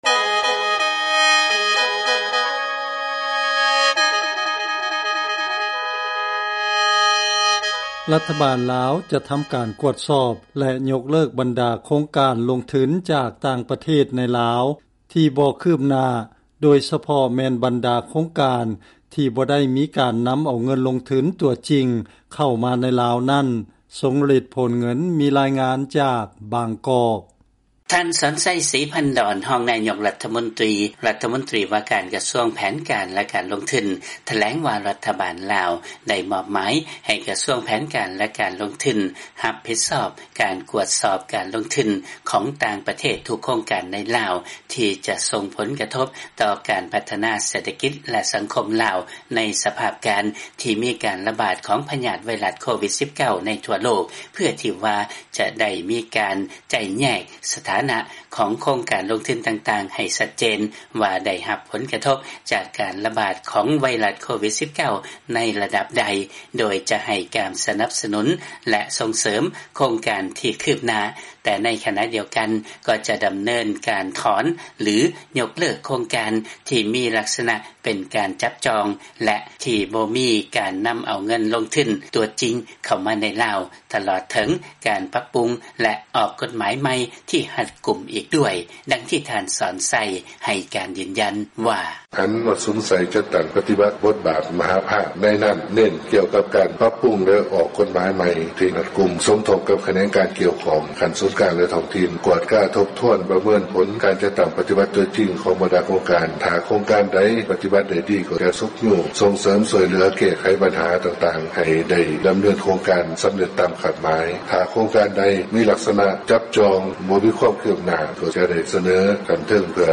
ເຊີນຟັງລາຍງານກ່ຽວກັບລັດຖະບານຈະຍົກເລີກໂຄງການລົງທຶນທີ່ບໍ່ມີຄວາມຄືບໜ້າ